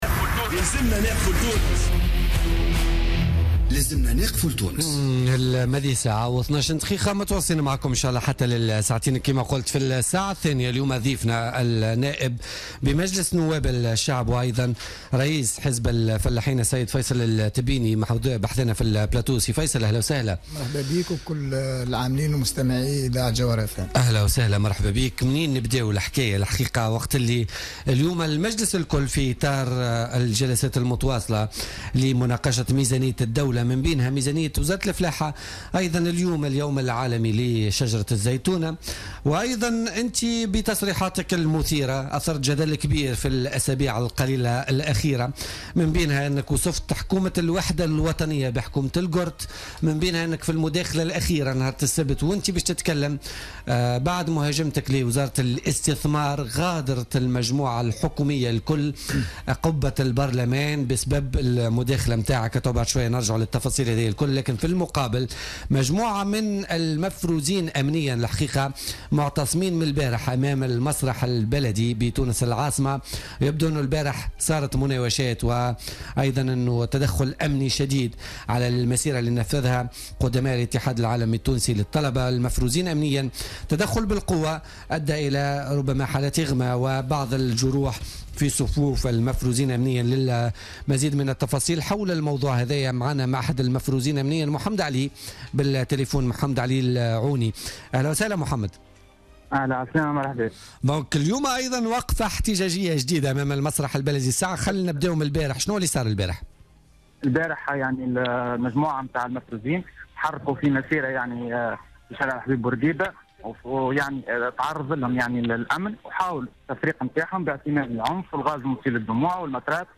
مداخلة له في بوليتيكا